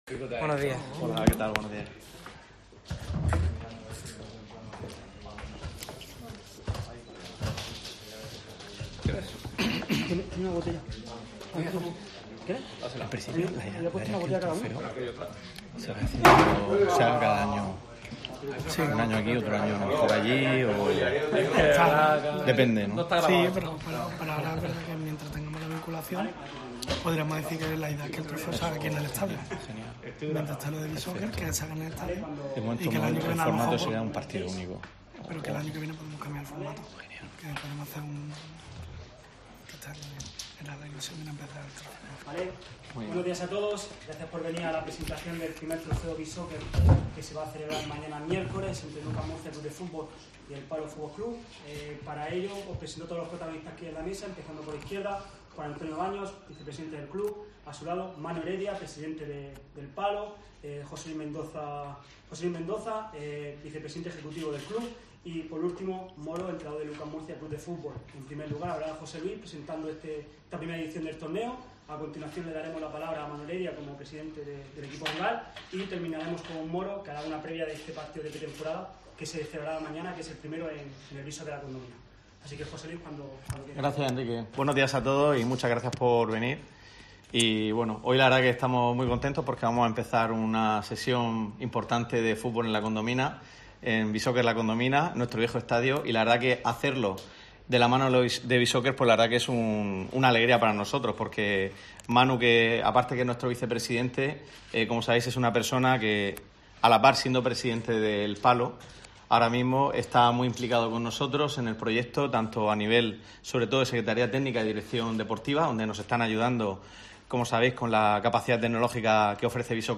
Este martes se ha presentado en rueda de presa el I Torneo BeSoccer que se celebrará este miércoles 10 de agosto, a las 20h, entre el Universidad Católica de Murcia Club de Fútbol y El Palo Fútbol Club sobre el césped del BeSoccer La Condomina.